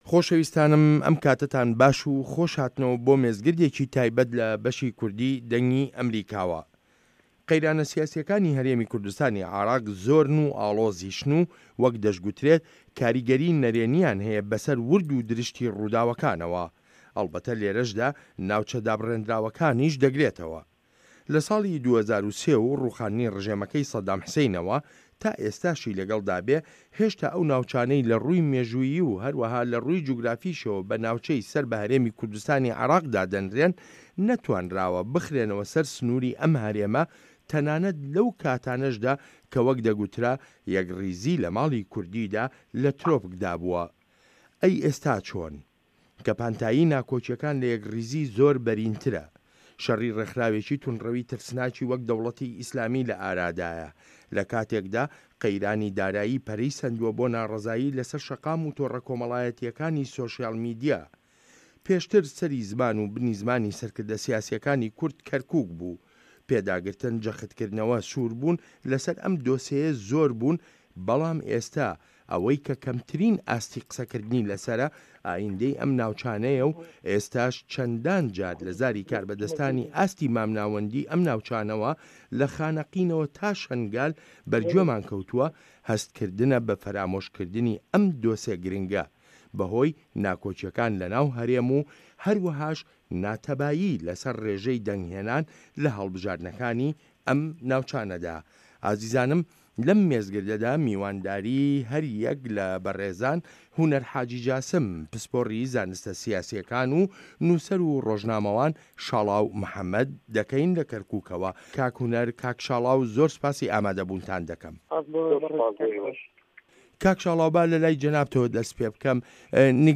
مێزگرد: قەیرانی سیاسی هەرێم و ئایندەی کەرکوک